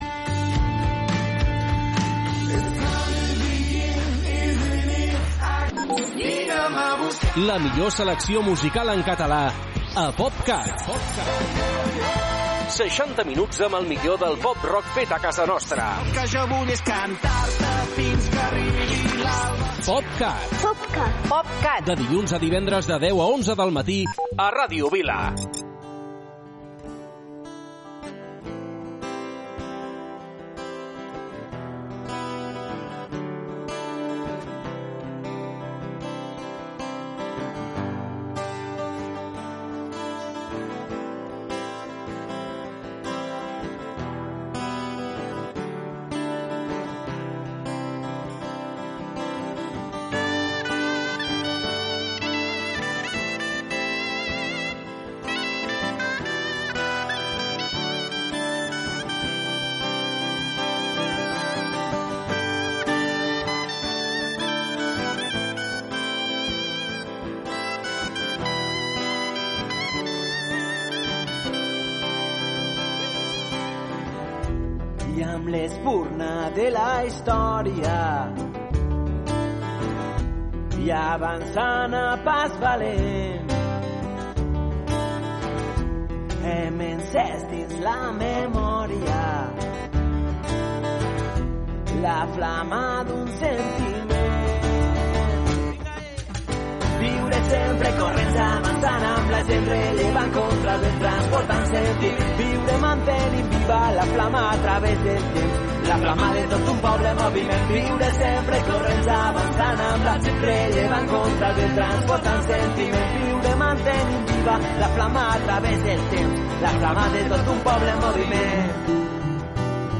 60 minuts de la millor música feta a casa nostra.